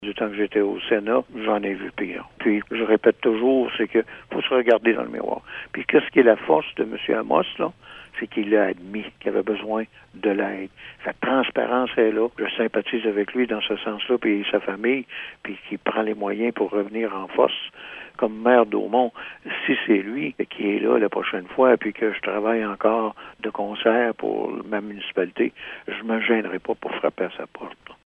Même son de cloche de la part du maire d’Aumond, Alphée Moreau. Il estime que les gens sont prêts à lui donner une autre chance. De plus, M. Moreau a répété à plusieurs reprises que personne ne peut se targuer de ne jamais avoir commis de gestes qu’il aurait aimé mieux ne pas avoir commis.